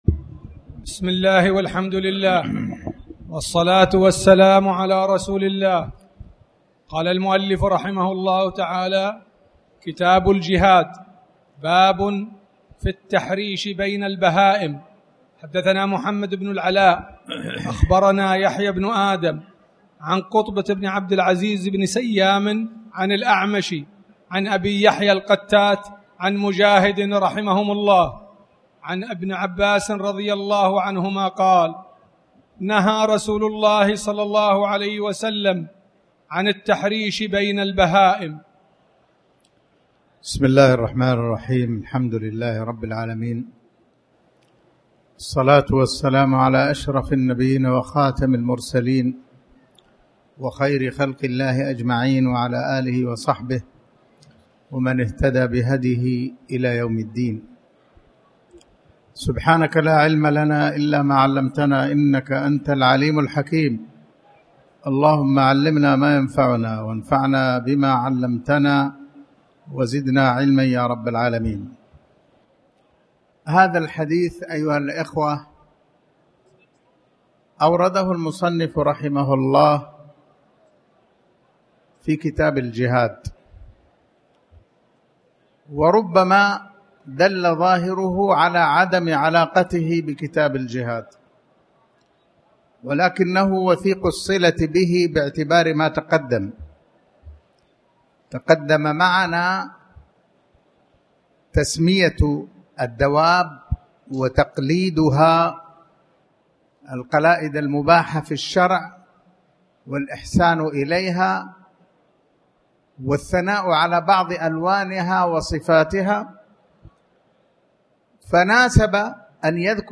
تاريخ النشر ١٠ محرم ١٤٣٩ هـ المكان: المسجد الحرام الشيخ